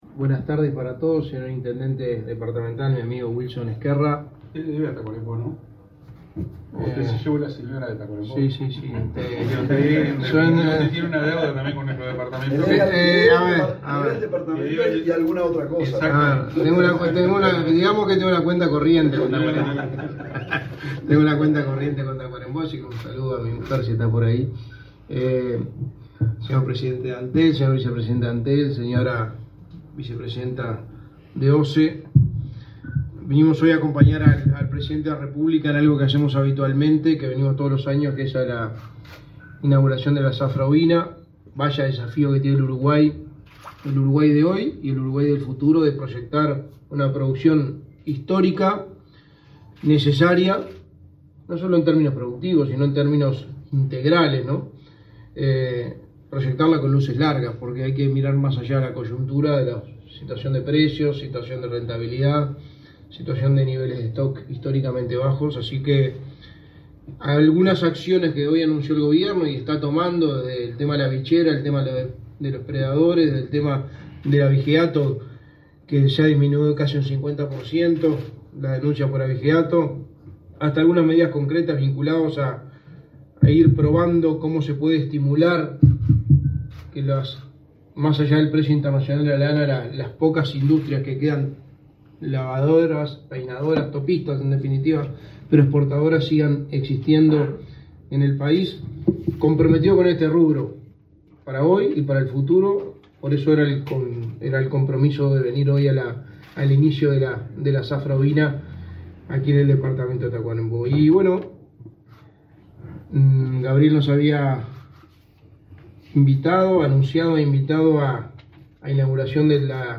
Palabras del secretario de la Presidencia, Álvaro Delgado
Palabras del secretario de la Presidencia, Álvaro Delgado 10/08/2023 Compartir Facebook X Copiar enlace WhatsApp LinkedIn El secretario de la Presidencia, Álvaro Delgado, participó en la habilitación de Antel de la tecnología 5G del servicio de conectividad móvil en Tacuarembó. En el evento, realizó declaraciones.